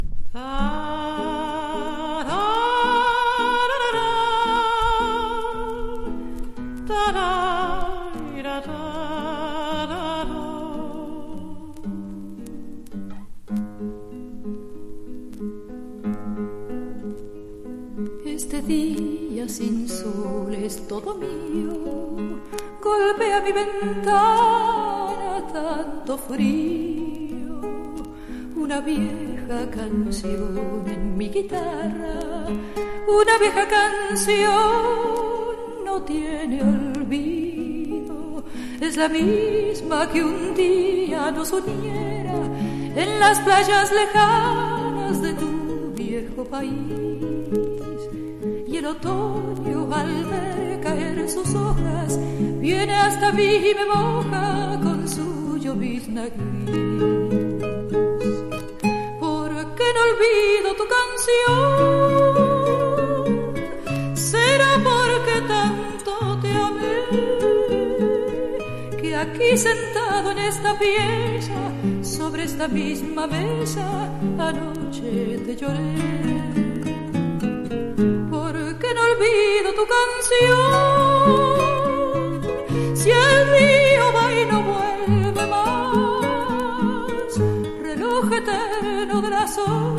ラテン・ファンの間で有名なアルゼンチン出身の女性歌手
LATIN